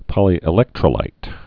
(pŏlē-ĭ-lĕktrə-līt)